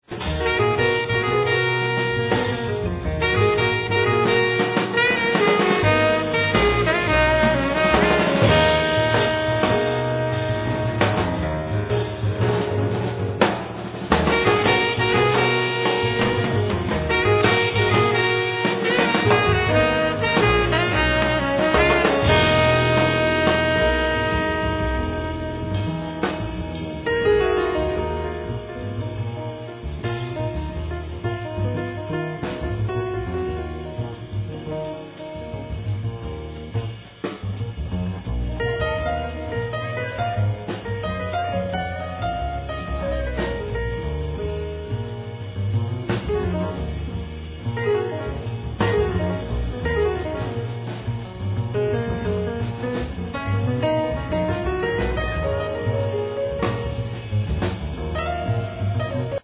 Piano
Drums
Double bass
Saxophone